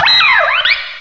cry_not_gothorita.aif